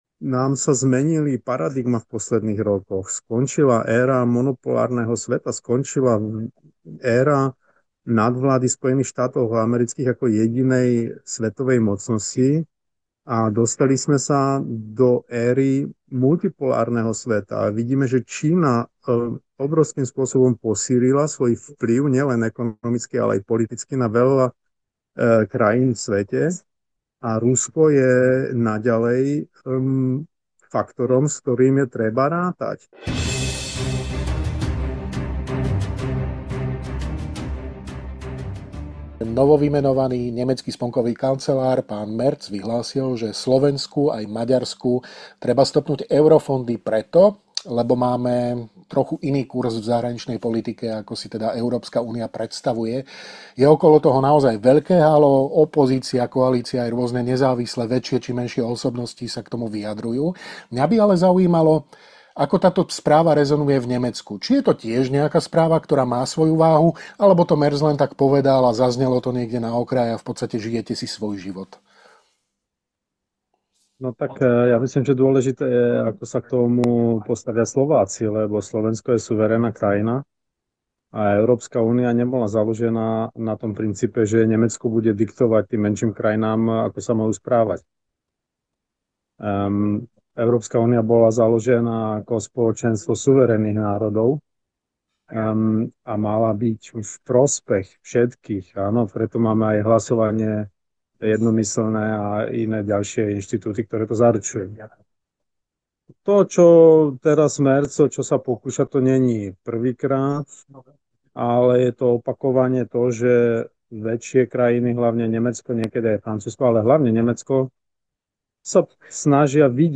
Rozprávali sme sa s europoslancom a bavorským zemským šéfom AfD, Petrom Bystroňom.
Viac vo video rozhovore.